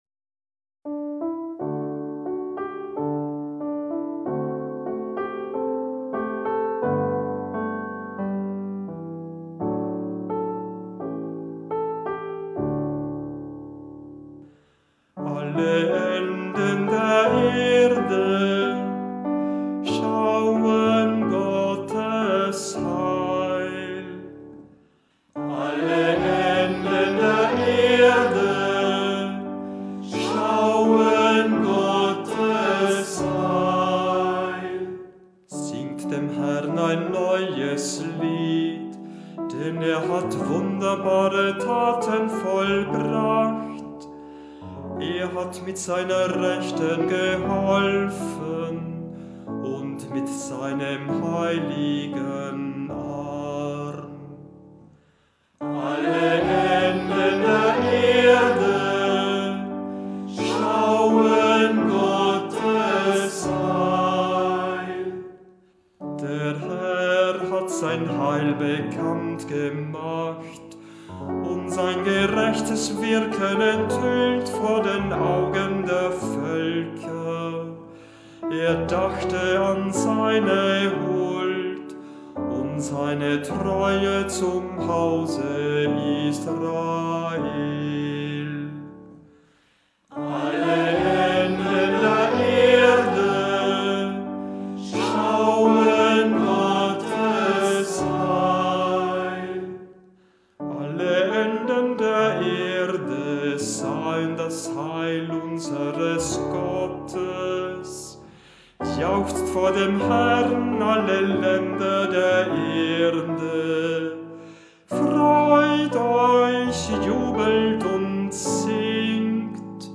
Sie dienen als ersten Höreindruck der Psalmen, bei denen der Kehrvers als fester Bestandteil integriert ist und deshalb umso schöner erklingen, je mehr sich beim Singen des Kehrverses beteiligen.